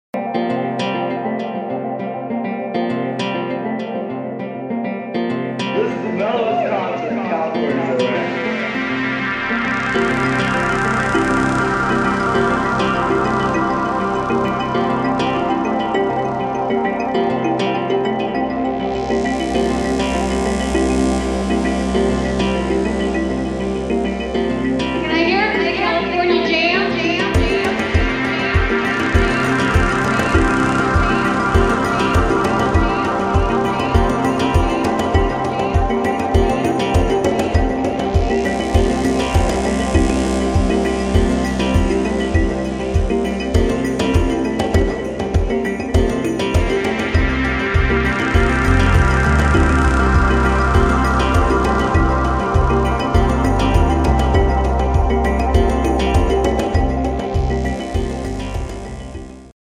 [ BALEARIC / DISCO / DOWNTEMPO ]
UKバレアリック・デュオ